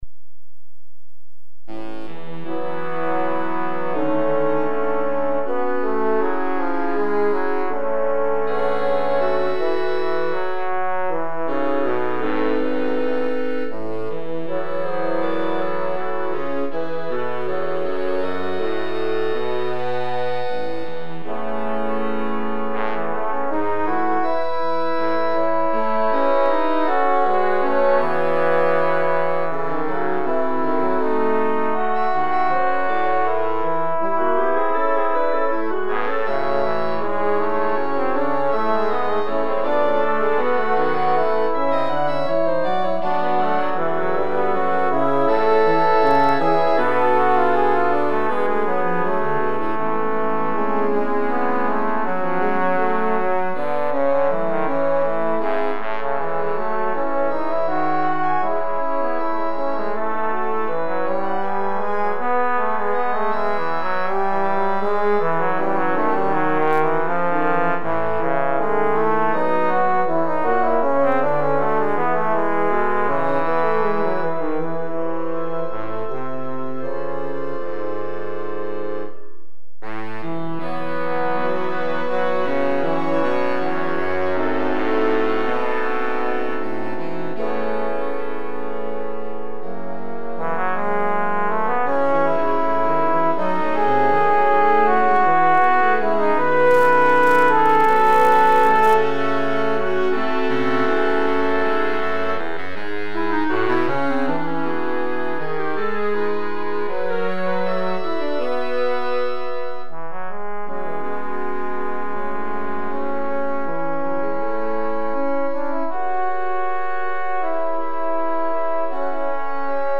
for Trombone and Winds